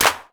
DrClap8.wav